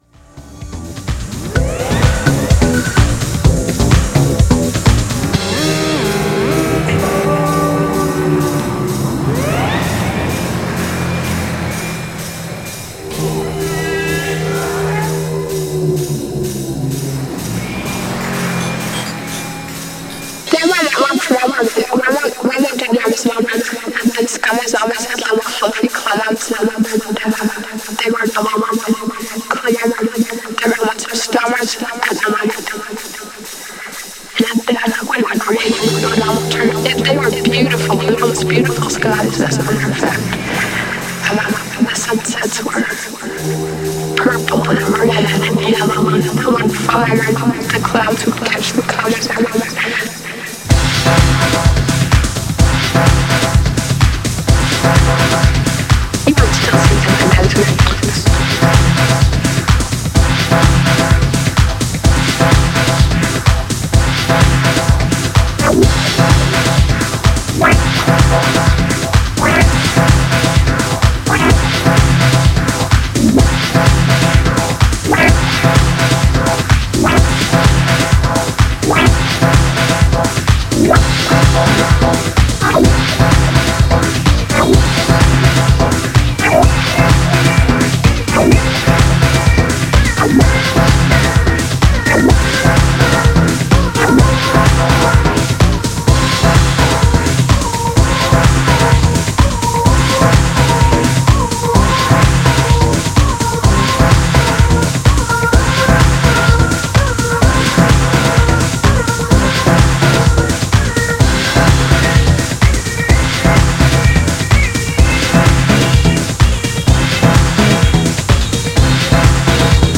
GENRE House
BPM 101〜105BPM